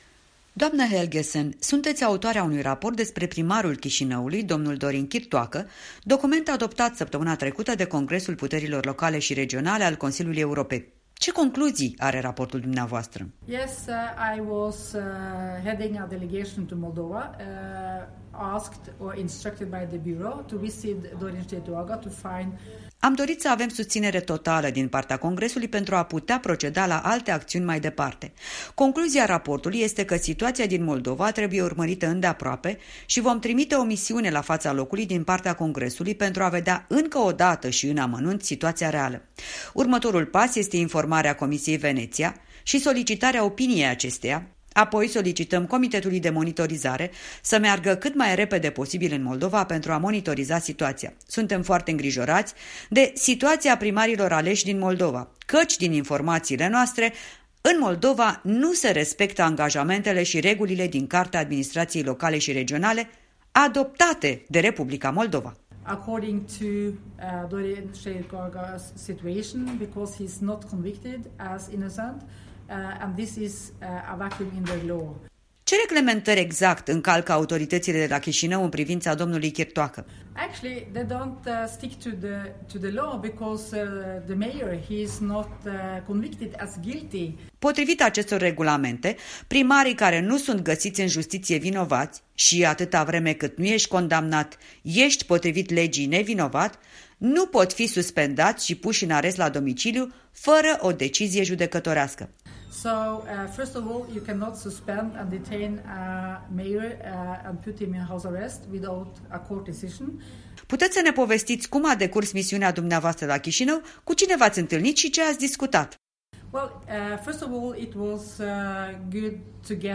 Un interviu la Strasbourg cu Gunn-Marit Helgesen, președintă a Camerei regiunilor